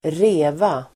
Uttal: [²r'e:va]